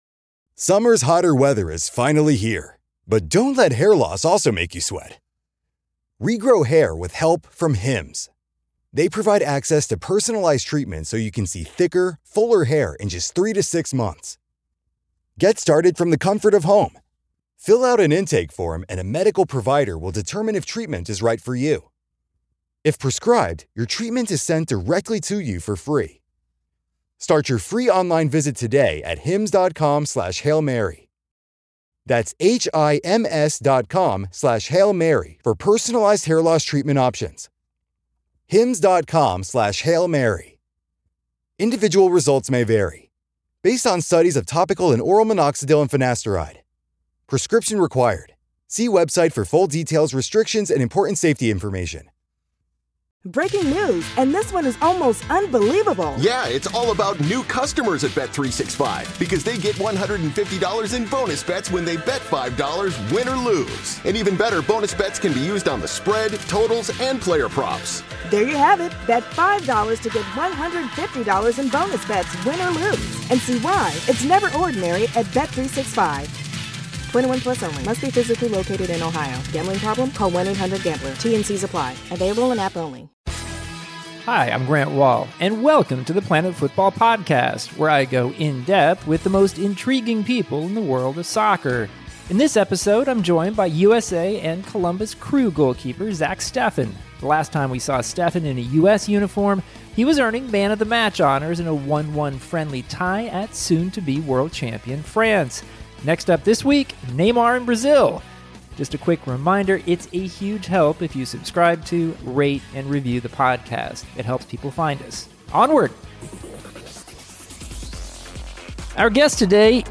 An Interview With Emerging USA Goalkeeper Zack Steffen